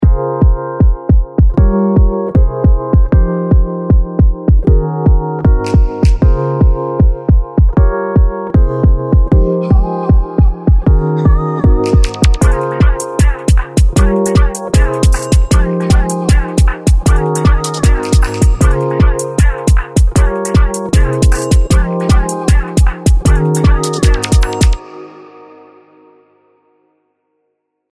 わかりやすく効果を紹介するにあたり短いトラックを作ってみましたので、ノーエフェクトの状態をご確認ください。